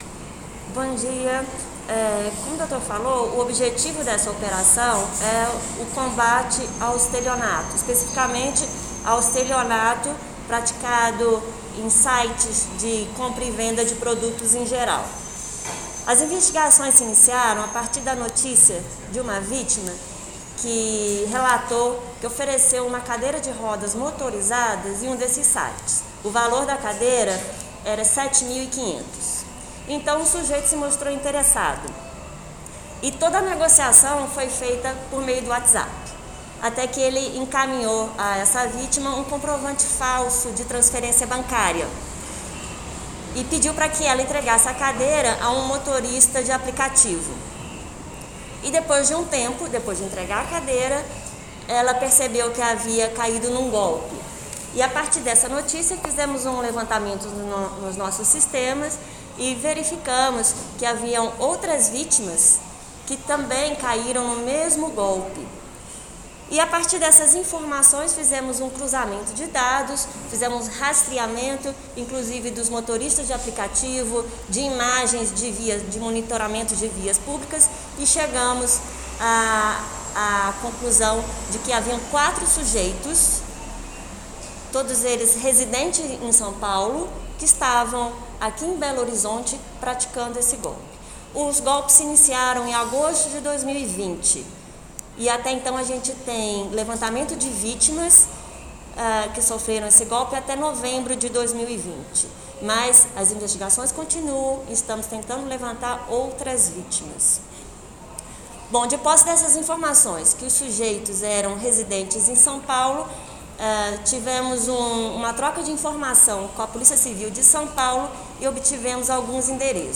O resultado dos trabalhos foi apresentado, na manhã desta quarta-feira (6), em coletiva de imprensa.
Coletiva-de-imprensa.mp3